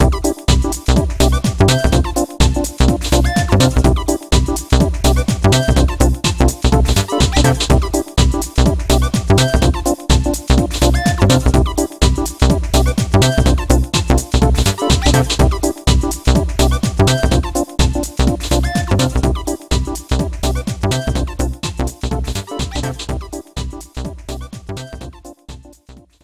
The music for name entry